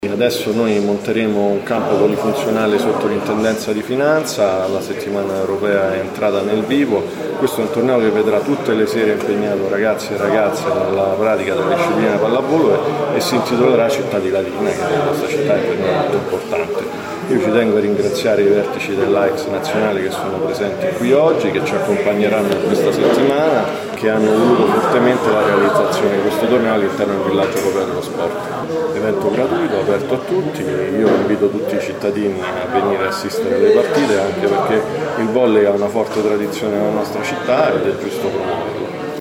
Ieri pomeriggio, presso la sala De Pasquale del Comune, la conferenza stampa di presentazione della prima edizione del Torneo di Pallavolo “Città di Latina”, che prenderà il via oggi con il Villaggio Europeo dello Sport.
Il torneo organizzato da AICS Latina si svolgerà dal 23 al 28 settembre presso Piazza del Popolo di Latina, e vedrà sfidarsi sei squadre pronte a darsi battaglia ogni sera a partire dalle 20:15, come ha spiegato l’Assessore allo Sport del Comune di Latina Andrea Chiarato: